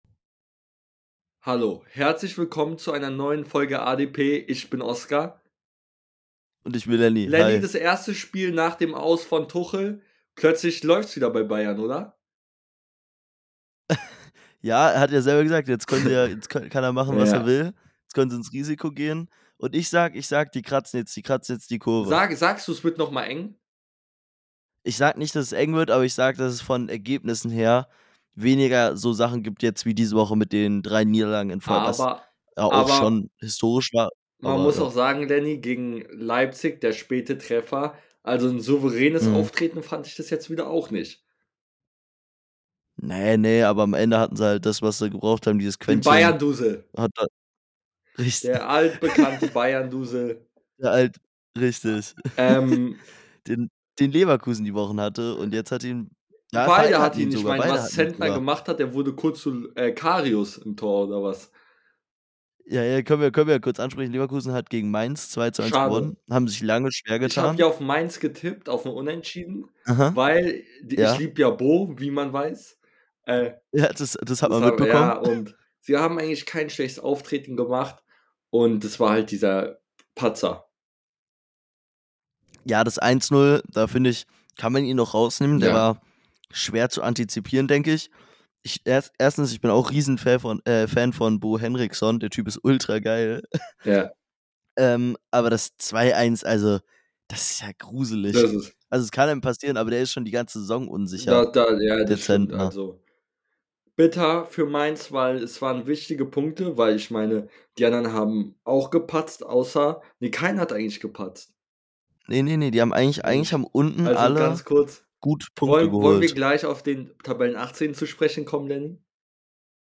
In der heutigen Folge reden die beiden Hosts über das Topspiel am Samstag , Darmstadts Pech , St Paulis Sieg und vieles mehr .